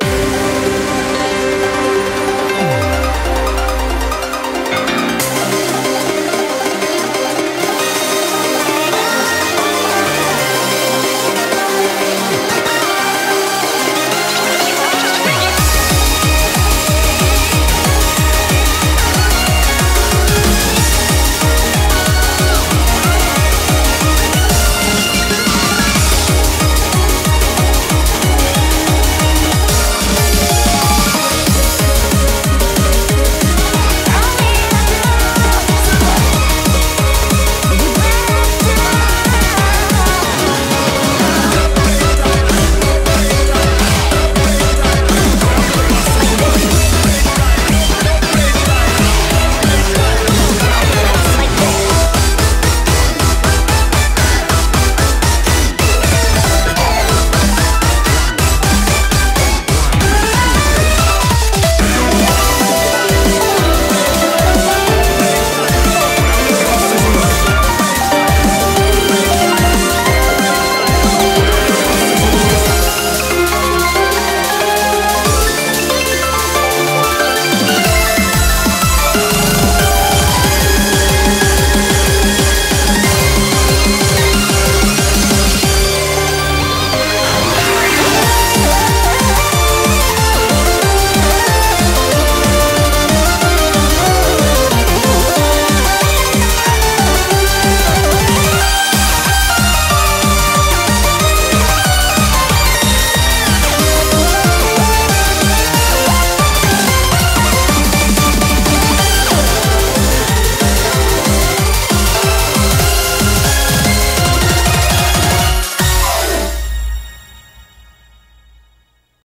BPM92-185